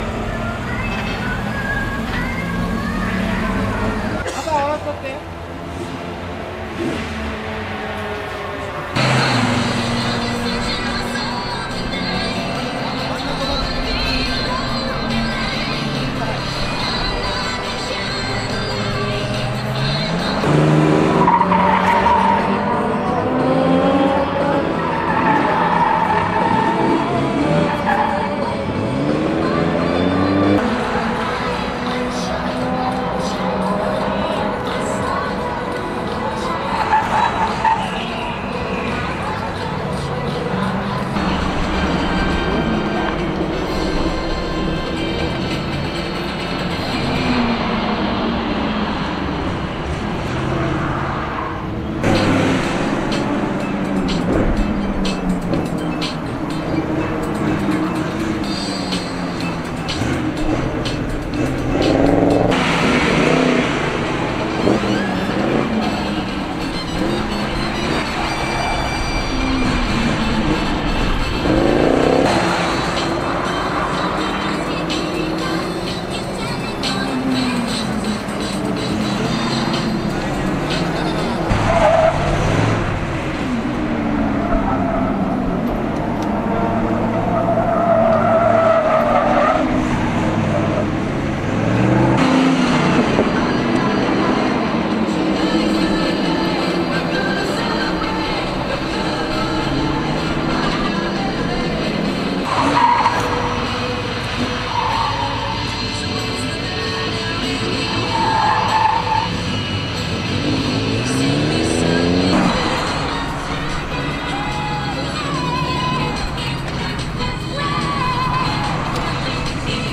筑波サーキットジムカーナ場
ジムカーナ風景